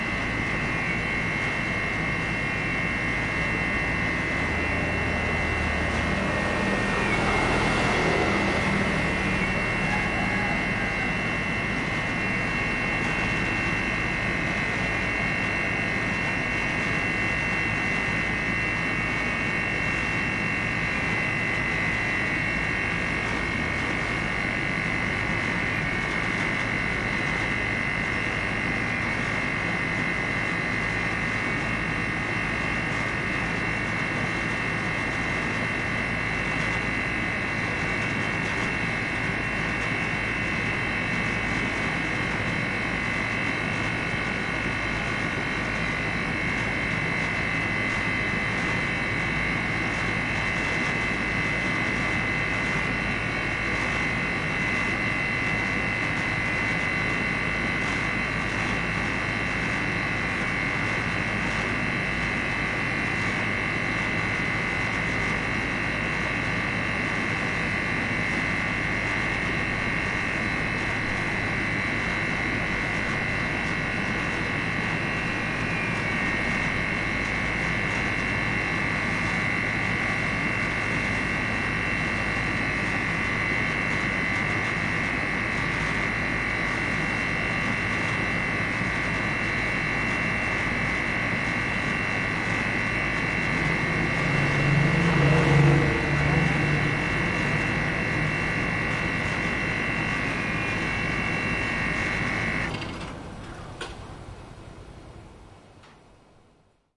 微波炉4分钟 门打开 关闭
描述：微波炉嗡嗡声，然后发出5声嘟嘟声，然后打开门。
在Zoom H2上以单声道mp3模式录制。